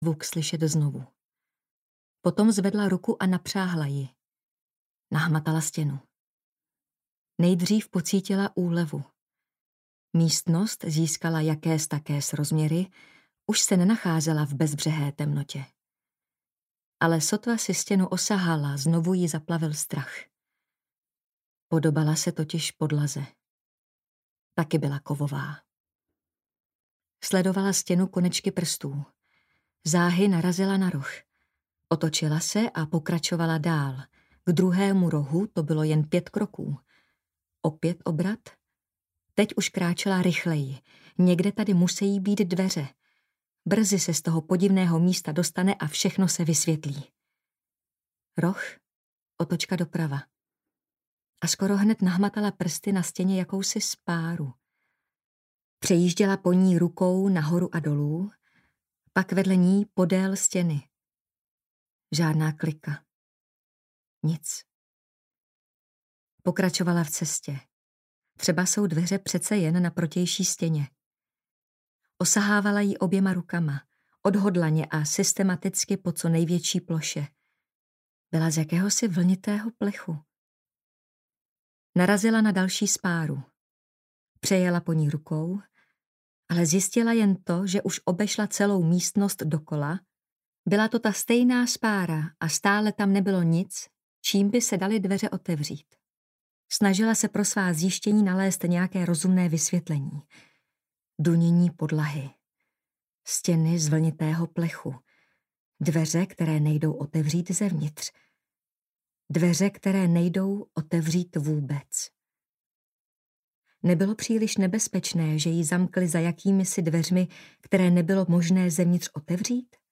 Dobrodinci audiokniha
Ukázka z knihy